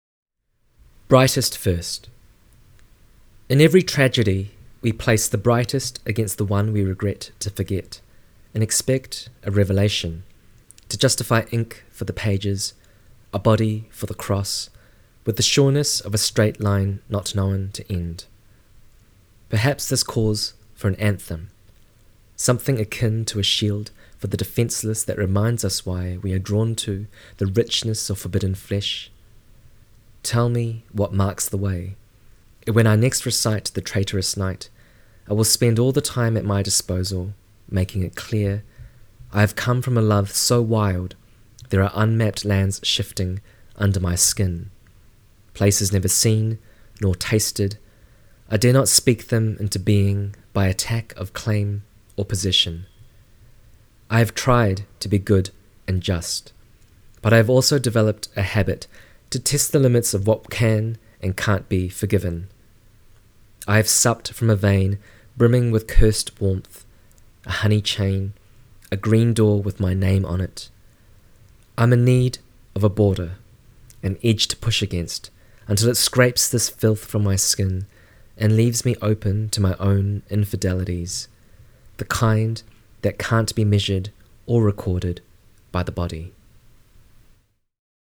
Chris reads ‘Brightest first’